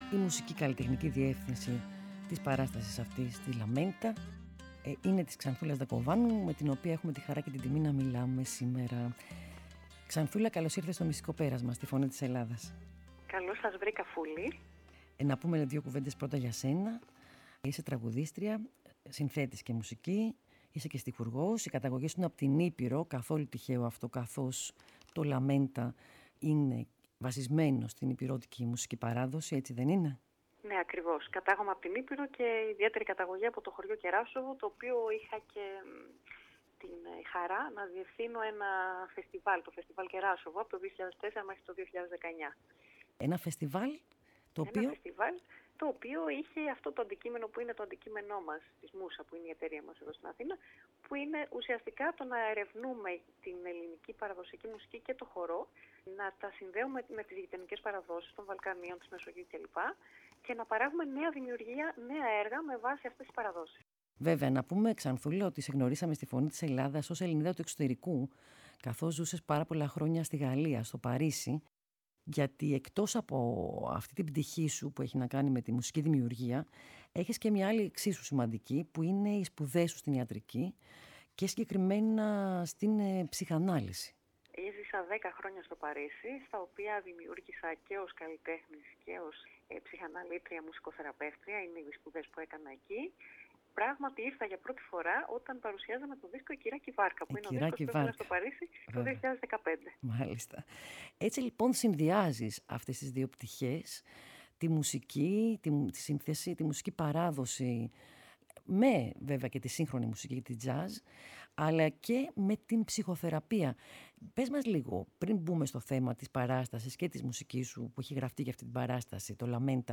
συνέντευξή